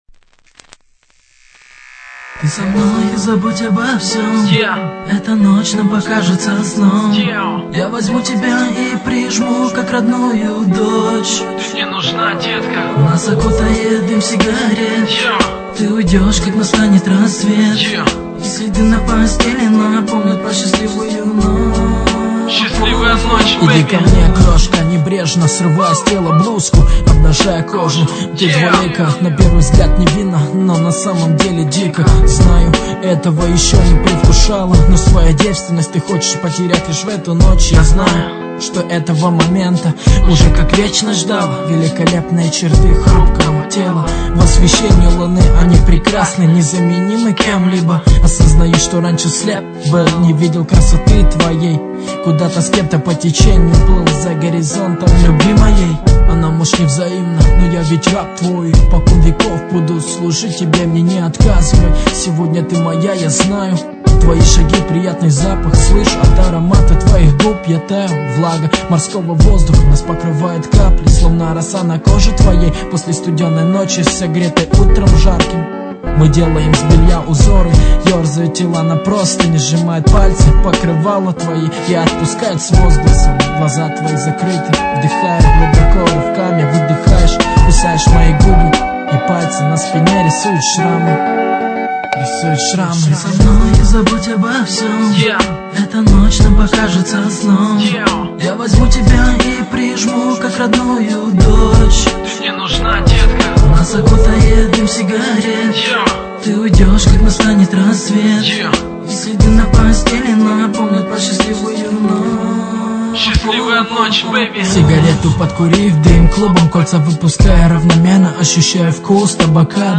За качество извиняйте, другой нету...